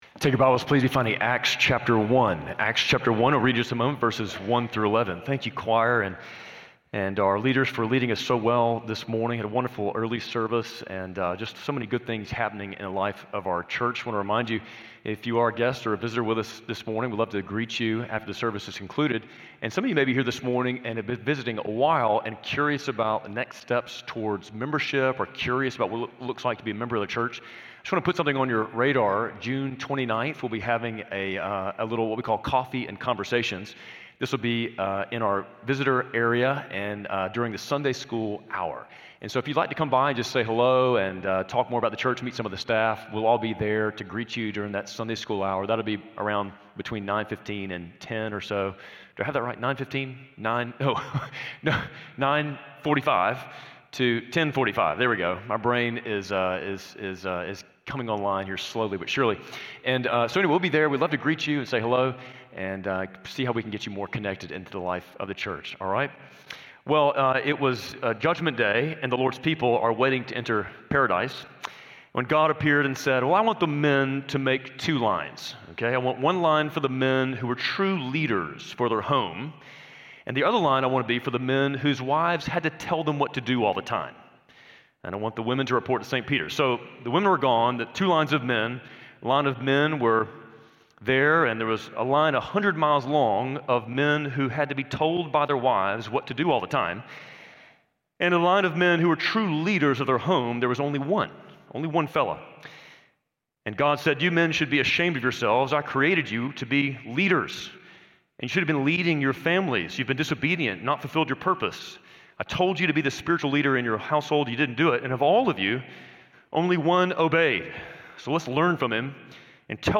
Spring Hill Baptist Sunday Sermons (Audio) / The Ascension of Christ